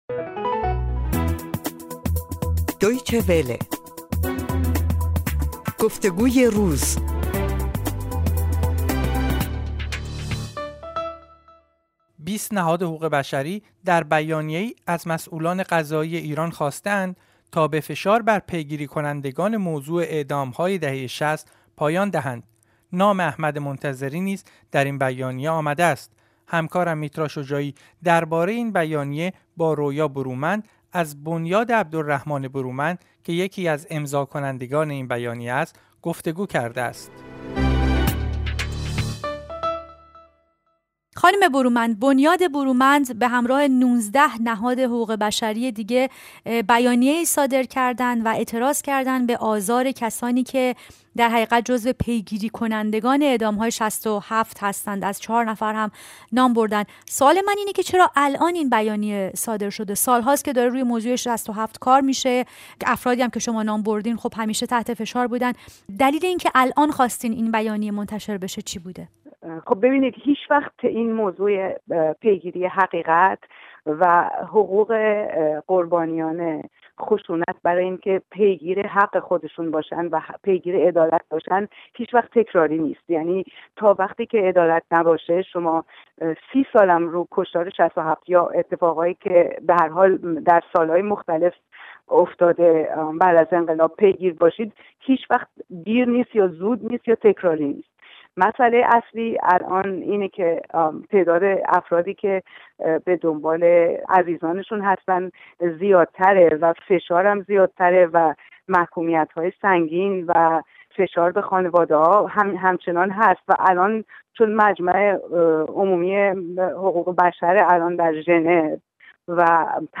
در گفتگو با دویچه وله: پیگیری حقیقت و عدالت هیچ وقت تکراری نیست